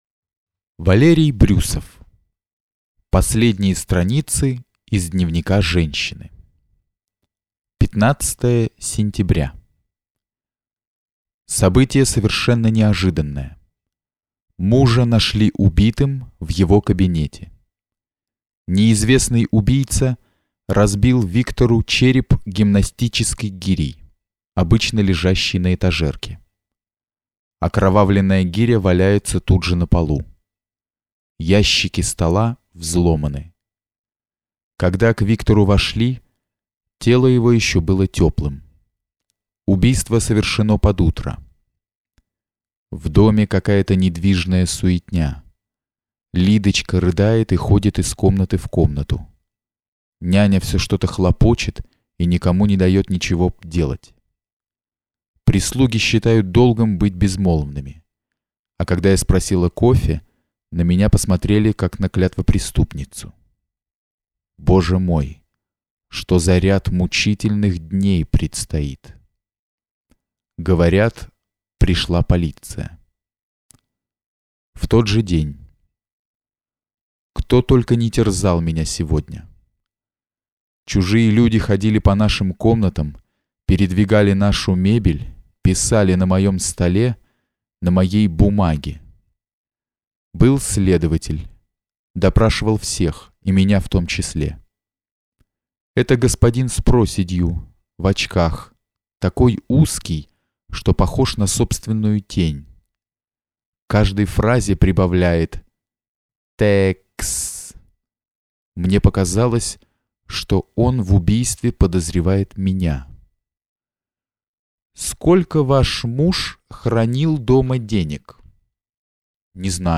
Аудиокнига Последние страницы из дневника женщины | Библиотека аудиокниг